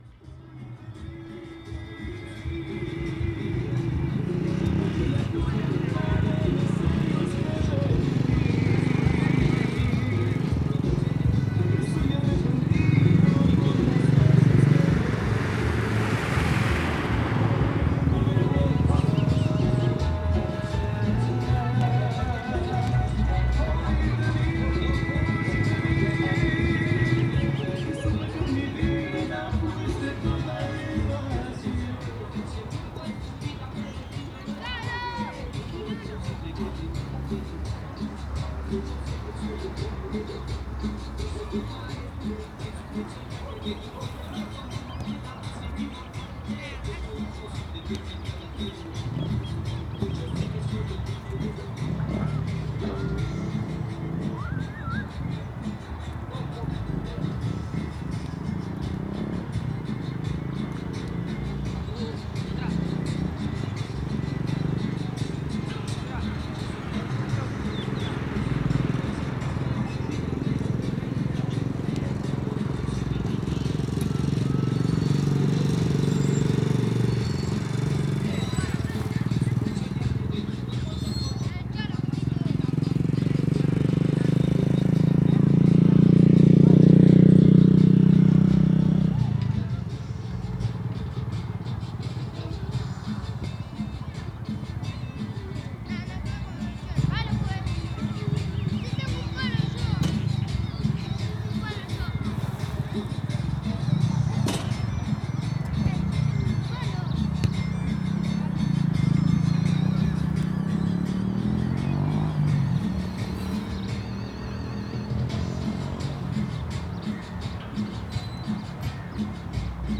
esf-granadero-baigorria-plaza-remanso-valerio.mp3